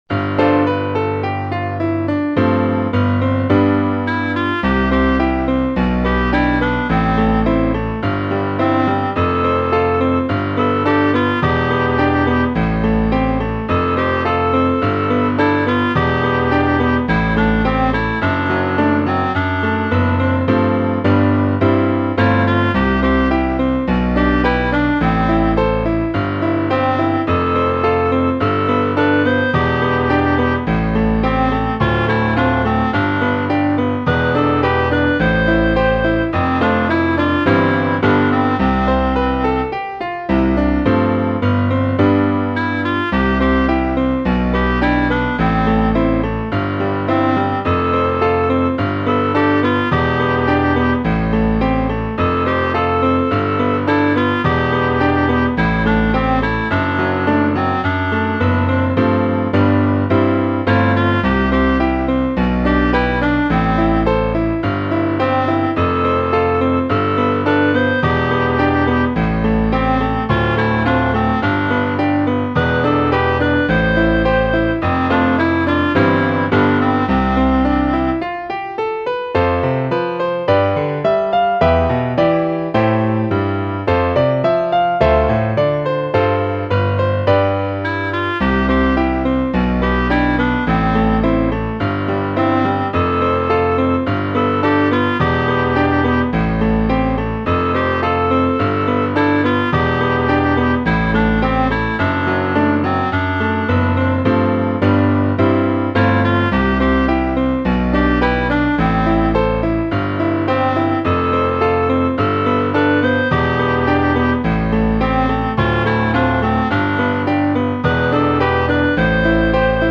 校歌　メロディあり-1.mp3